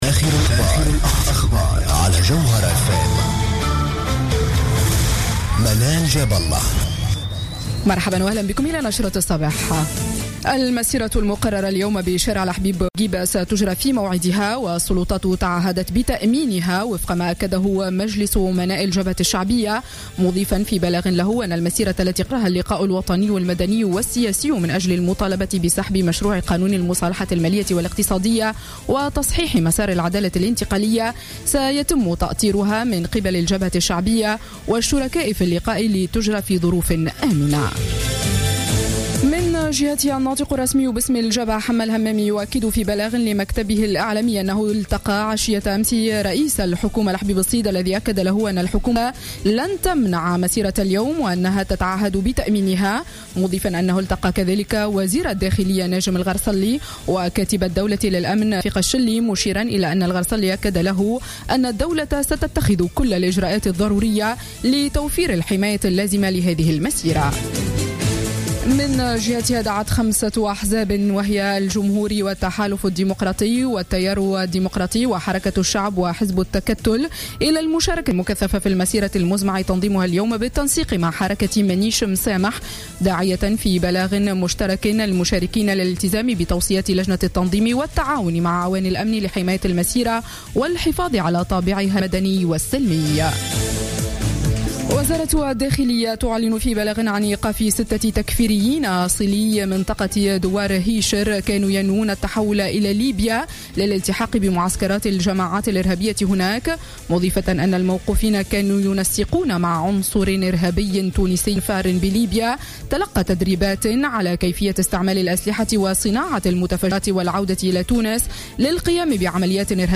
نشرة أخبار السابعة صباحا ليوم السبت 12 سبتمبر 2015